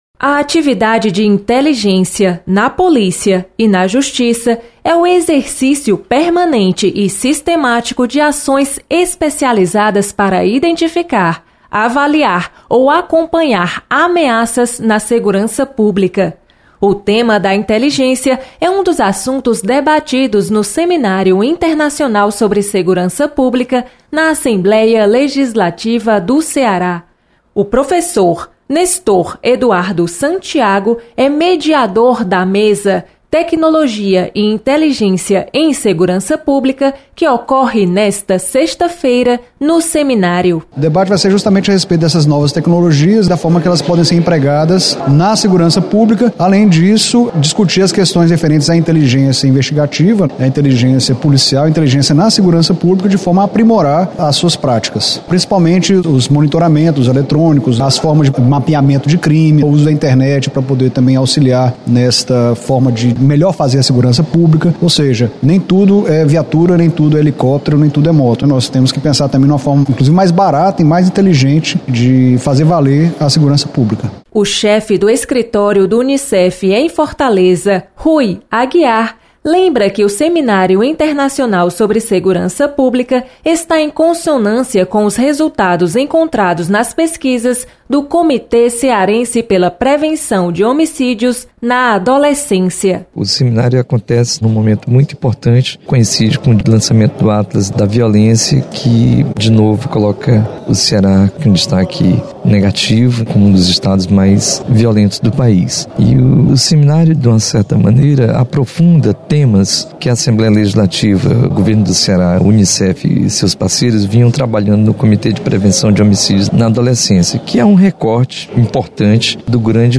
Especialistas destacam importância da inteligência policial no combate da criminalidade. Repórter